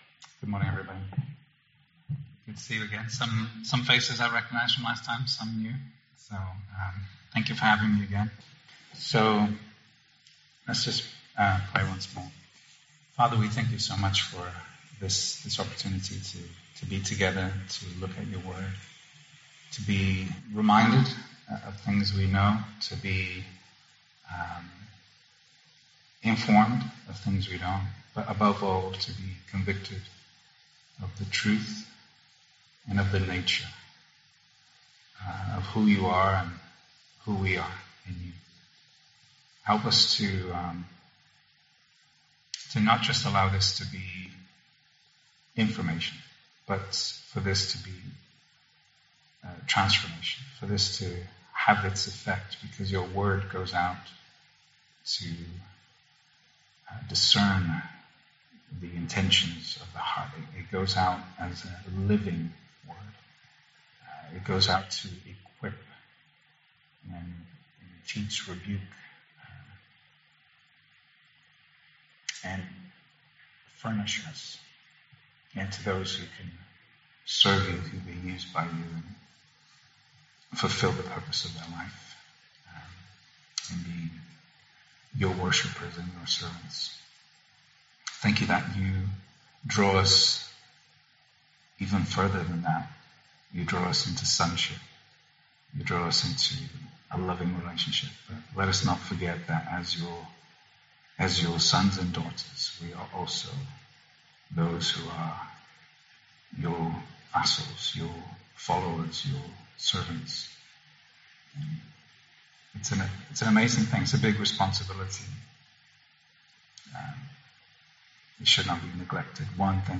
May you be blessed and encoueaged by this second part of a two-part study. 10 Series: Guest Speakers , Sunday morning studies , Topical Studies Tagged with guest speakers , The Eternal Gospel , topical studies